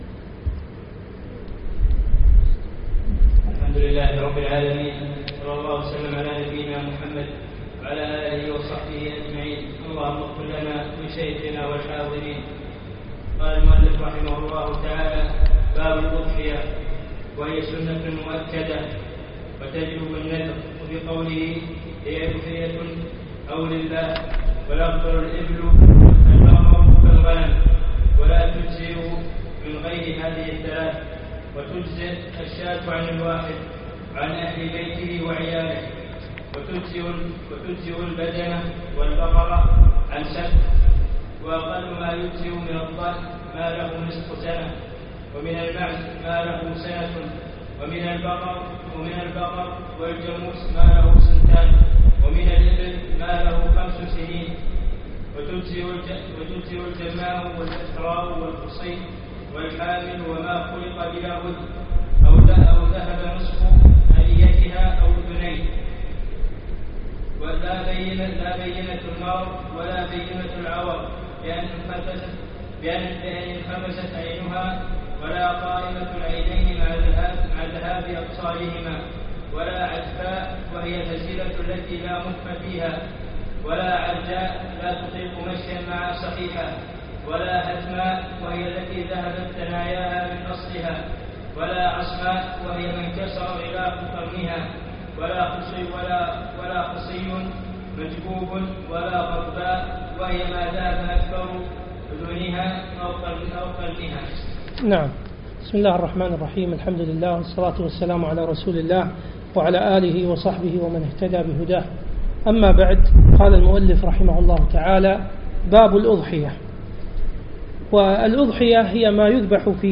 يوم الثلاثاء 24 ذو القعدة 1436 الموافق 8 9 2015 مسجد سالم العلي الفحيحيل
الدرس الثالث والأخير